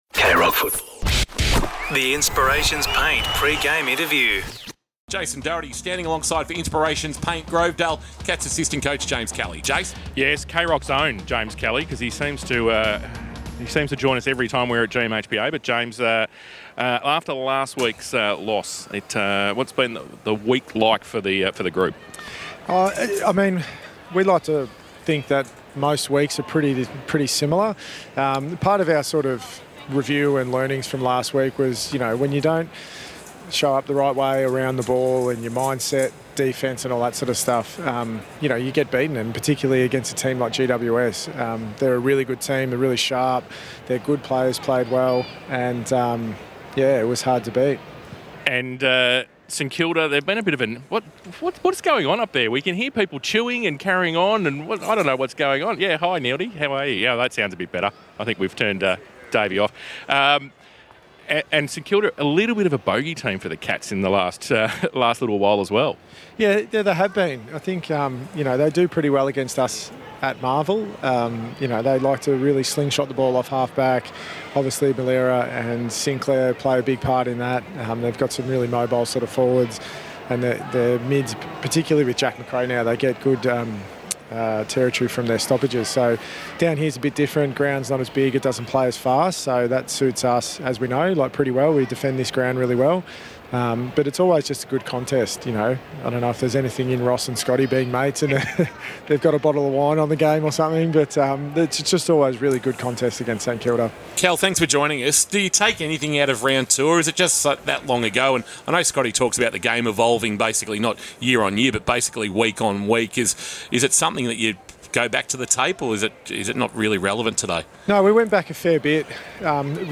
2025 - AFL - Round 19 - Geelong vs. St Kilda: Pre-match interview - James Kelly (Geelong asssistant coach)